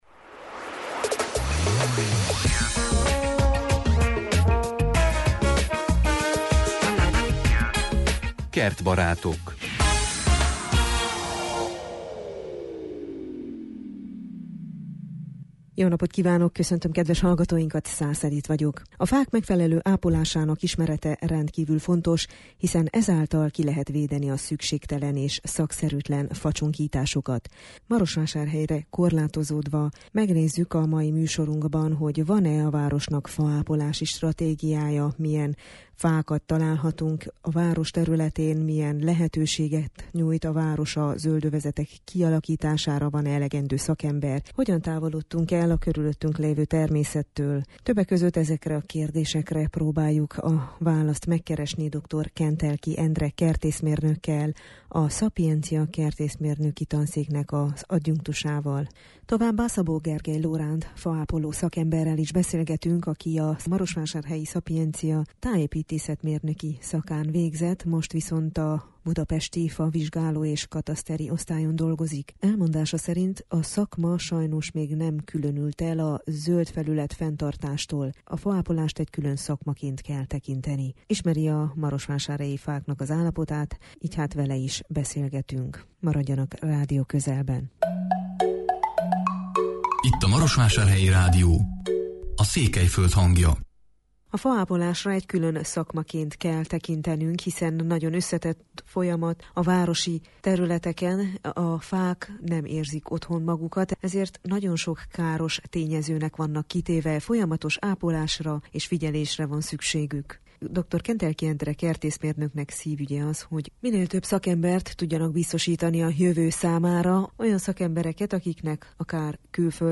A Kertbarátok október 5-én elhangzott műsorban többek között azokat a kérdéseket próbáltuk megválaszolni meghívottainkkal, amelyek a Marosvásárhely fáinak állapotáról, faápolási stratégiáról, faápolás, mint külön szakma és a szakemberek neveléséről szólnak.